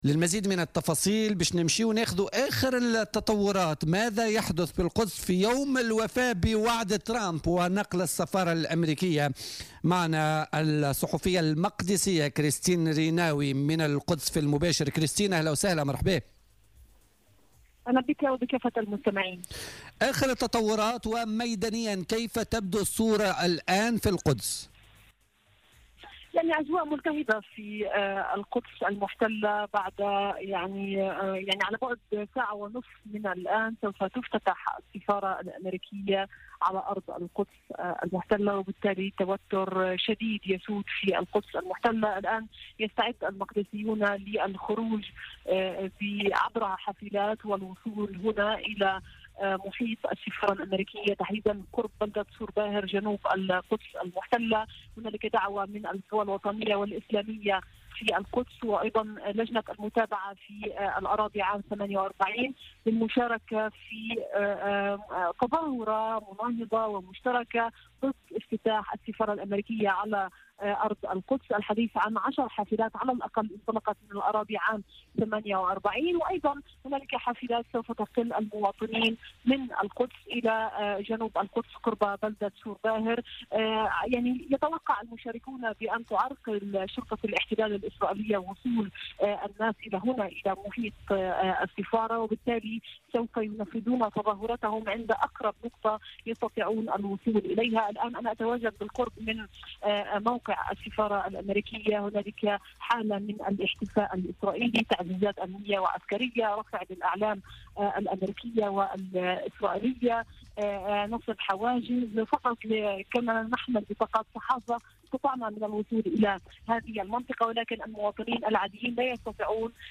مباشرة من الأراضي المحتلة : الوضع في القدس تزامنا مع افتتاح سفارة أمريكا